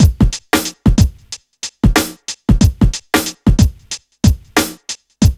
1HF92BEAT3-L.wav